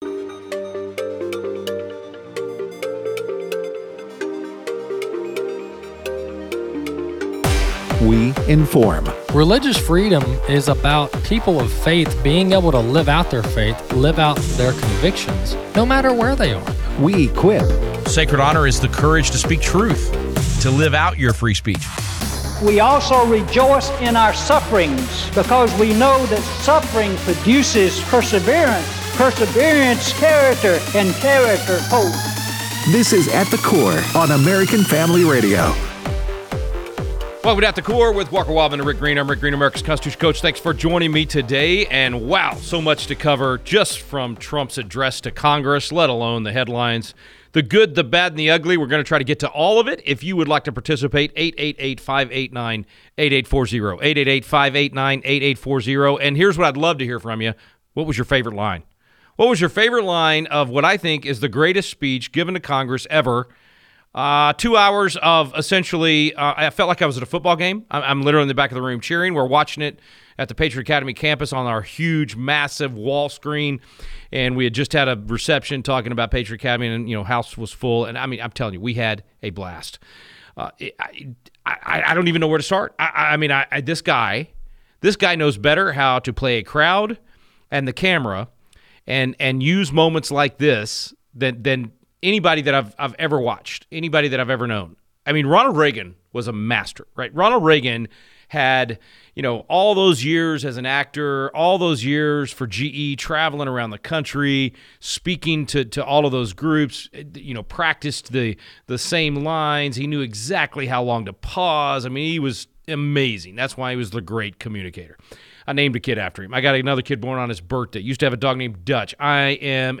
Callers push it up!